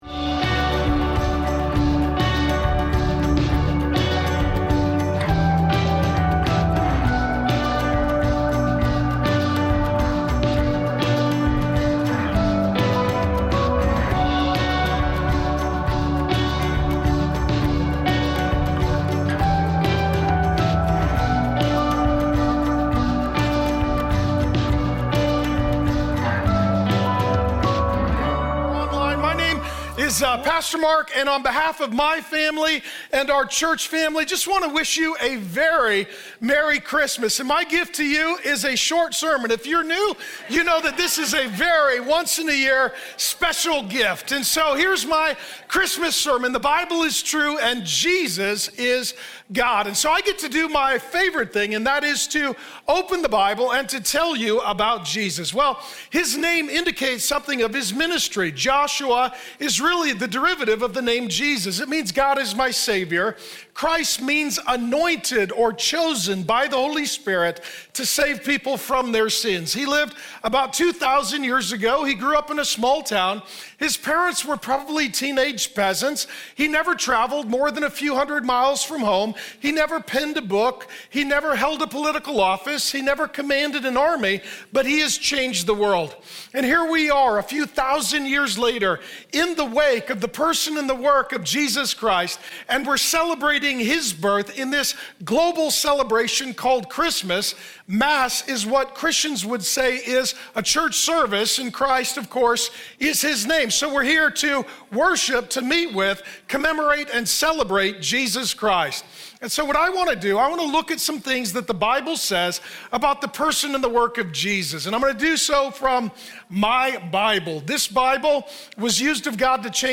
So here's my Christmas sermon: The Bible is true and Jesus is God.